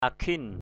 /a-kʰɪn/ (cv.) ukhin uA{N (đg.) cữ, kỵ, kiêng cử = défendre, interdire. defend; ban. akhin pakal aA{N pkL can hệ, kiêng kỵ = tabou, interdit. gaok akhin pakal _g<K aA{N pkL gặp...